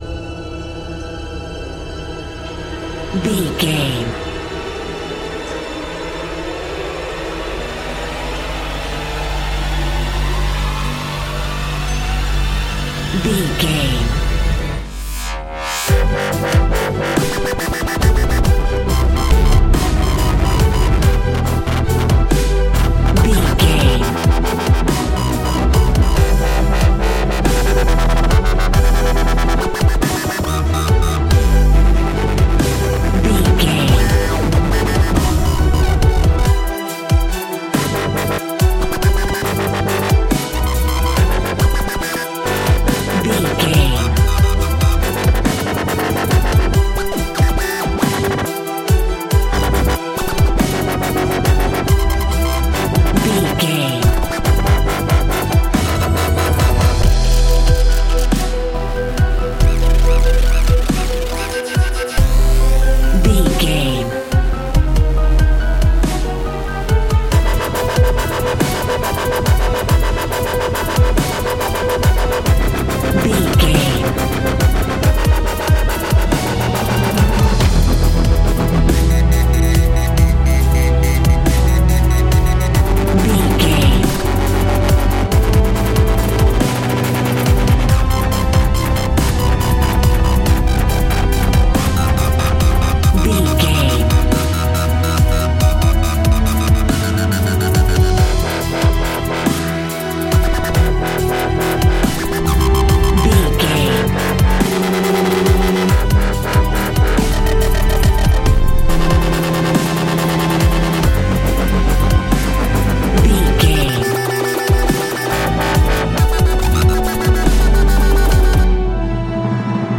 Epic / Action
Fast paced
In-crescendo
Aeolian/Minor
strings
drum machine
synthesiser
driving drum beat